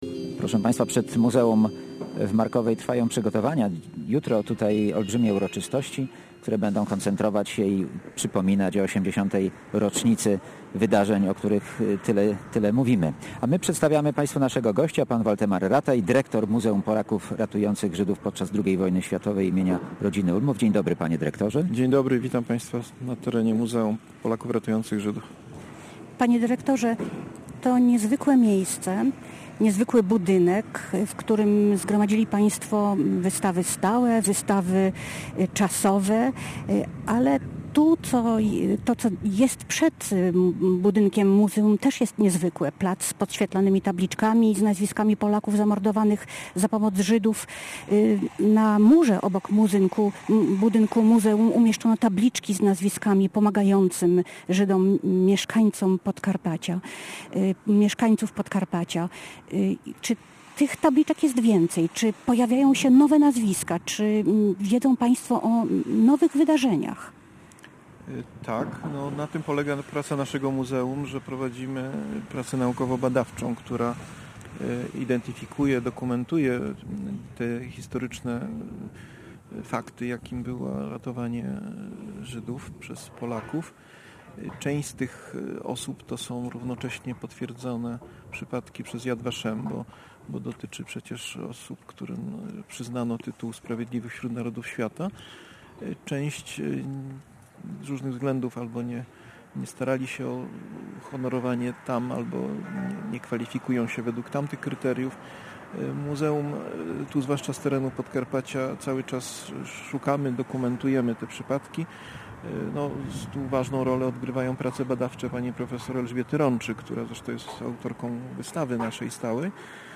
Z tej okazji Polskie Radio Rzeszów gościło w Muzeum Polaków Ratujących Żydów podczas II wojny światowej im. Rodziny Ulmów w Markowej. Rozmawialiśmy z osobami, które tragiczne wydarzenia sprzed lat inspirują do kultywowania pamięci o Polakach niosących pomoc ludności żydowskiej podczas II wojny światowej.